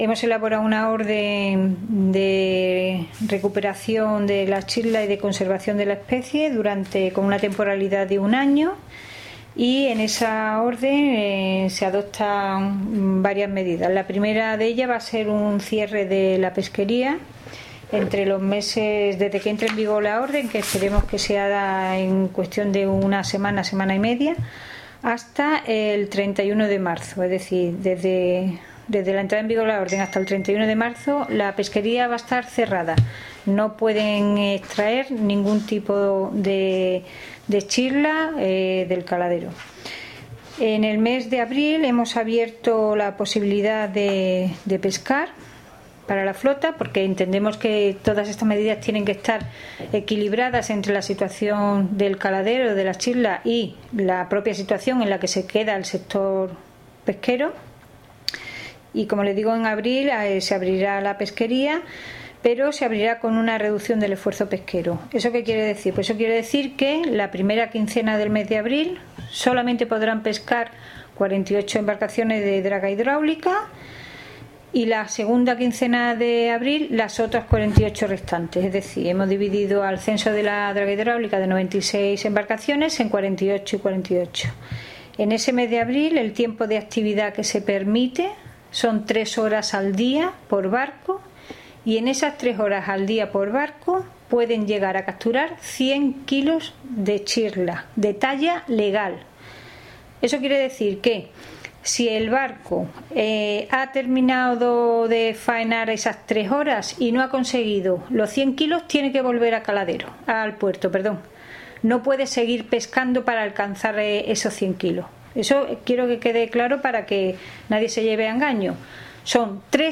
Declaraciones de Margarita Pérez sobre infracciones y seguimiento de la pesquería